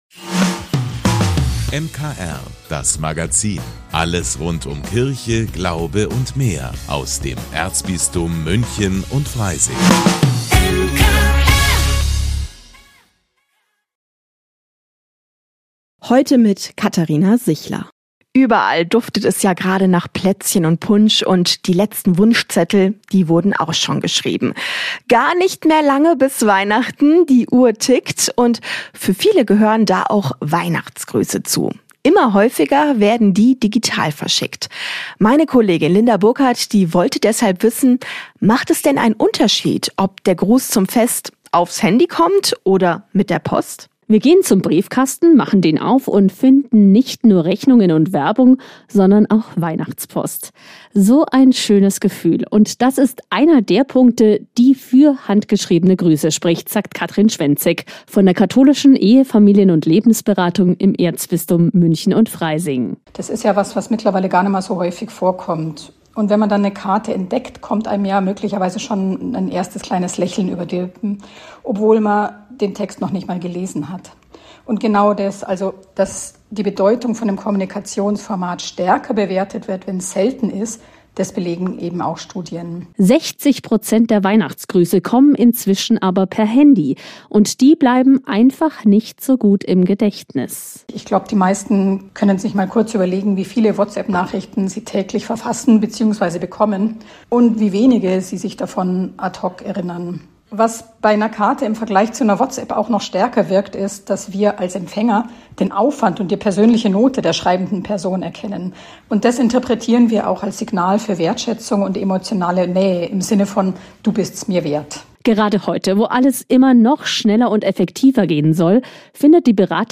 - Weihbischof Bischof liest Ludwig Thomas Weihnachtsgeschichte.